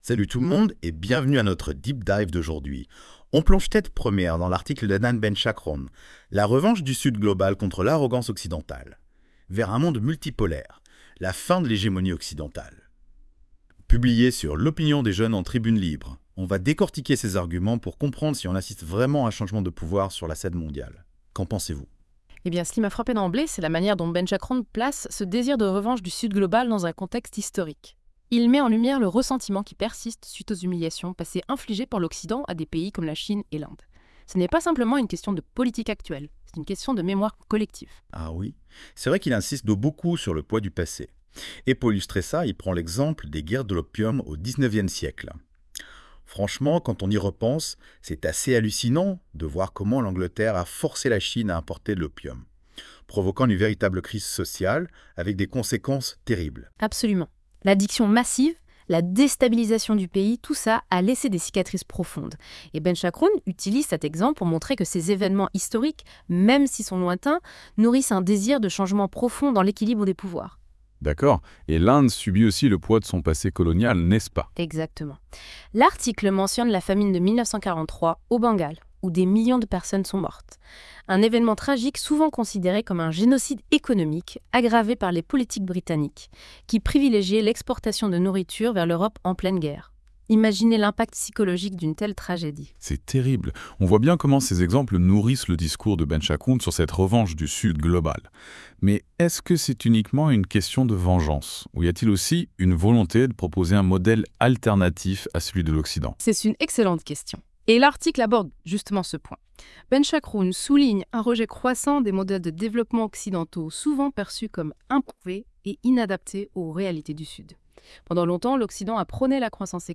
Débat à écouter en podcast (22.79 Mo)